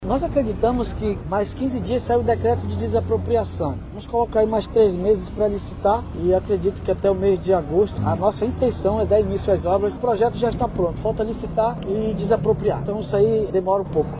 Questionado sobre o prazo de entrega da obra, o Chefe do Executivo Municipal disse que o local vai passar por um processo de desapropriação, e em seguida será dada a ordem de serviço.